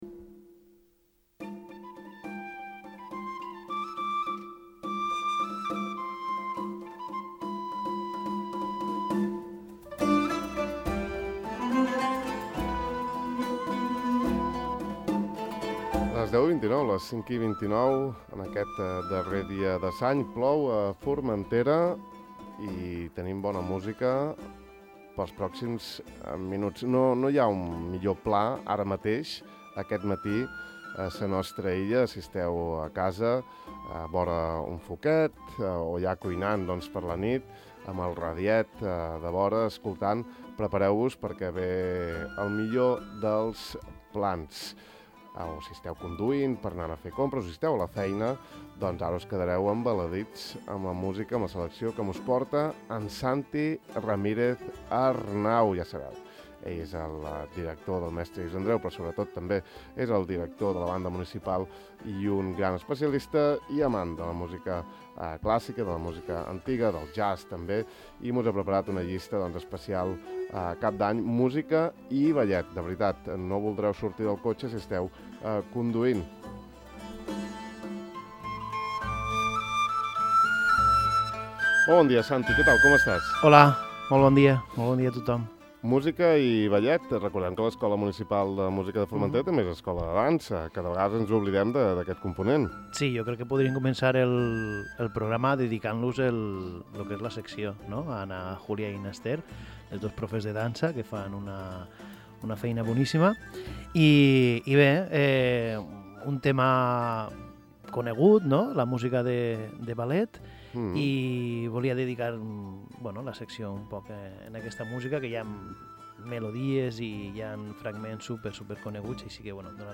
Rameau, Delibes, Tchaikovsky, Prokofiev, Stravinsky, de Falla, etc. Una autèntica delícia per l’oïda.